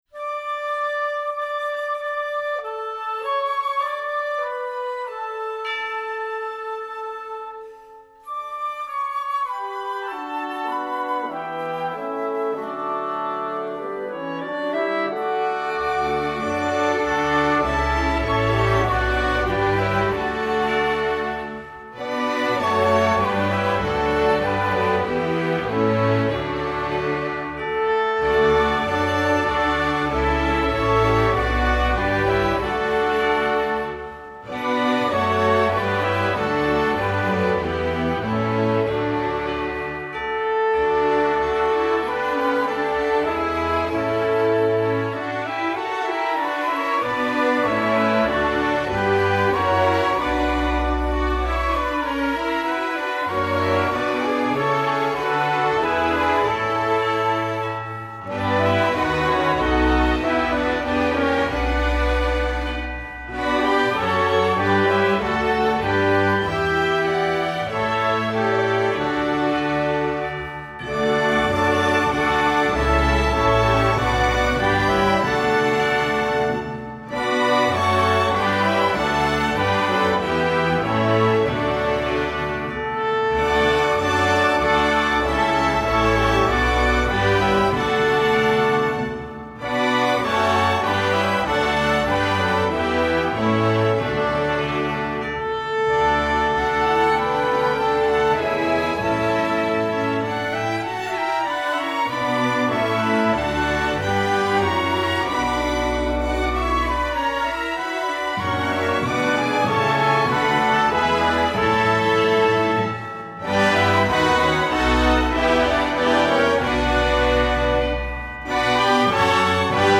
Voicing: Full Orche